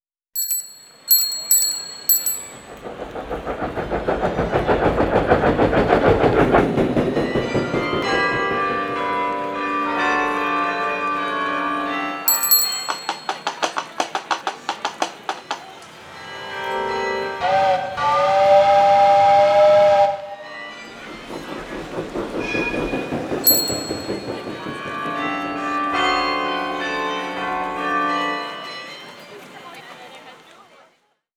Exemples de sonorités du CRDL